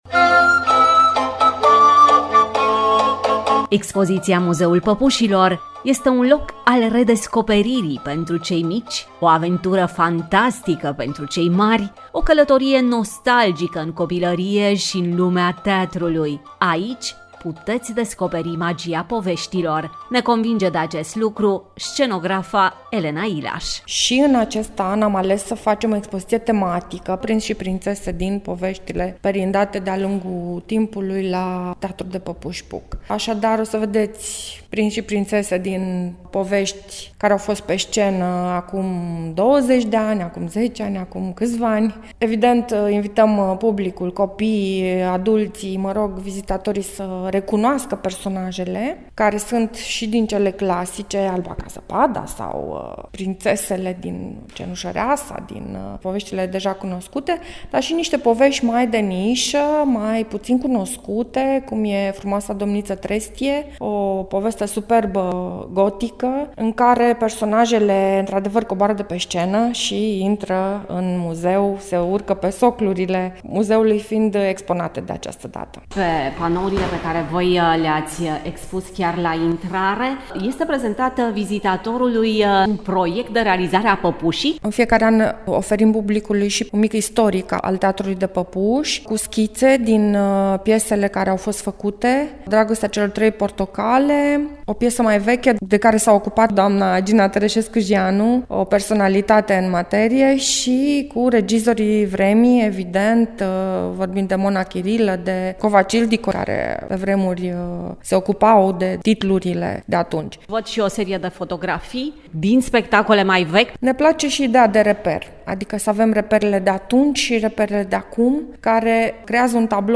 Prima pagină » Reportaje » Muzeul Păpușilor: artă, basm și tradiție